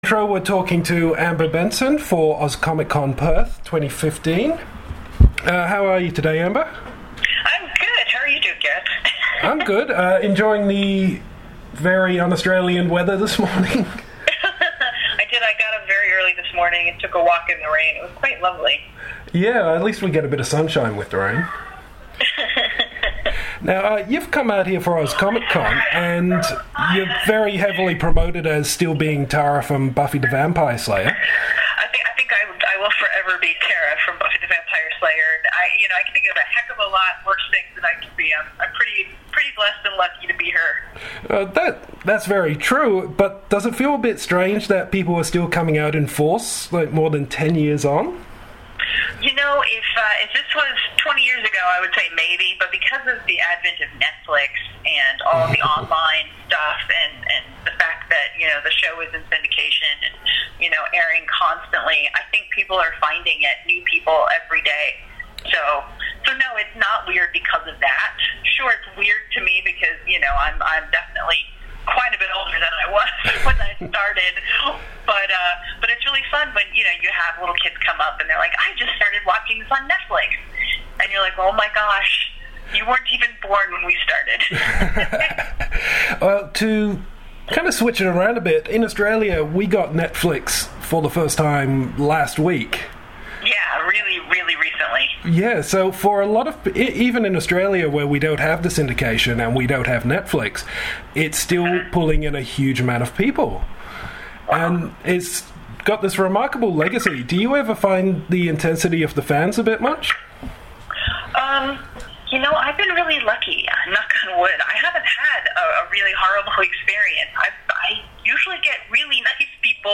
Exclusive Interview with Amber Benson!